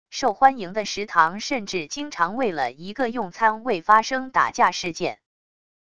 受欢迎的食堂甚至经常为了一个用餐为发生打架事件wav音频生成系统WAV Audio Player